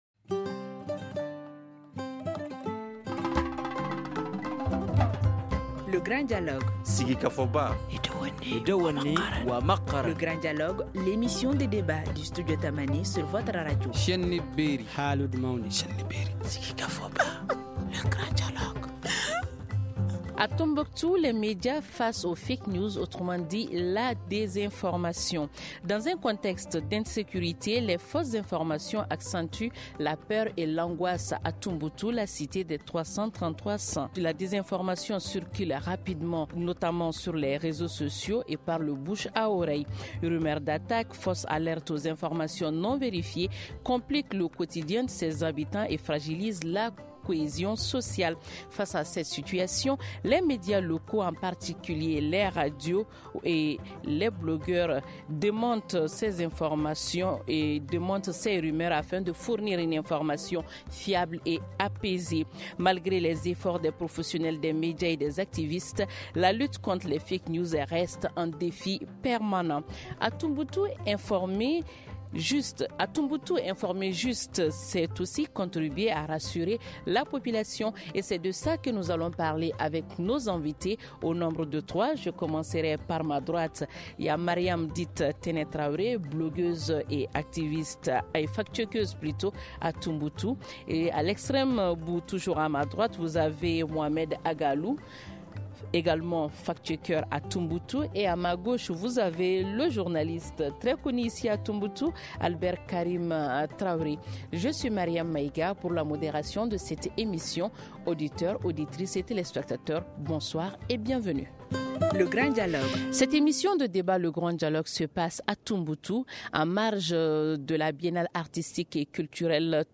Écoutez l’ intégralité de l’émission Grand Dialogue :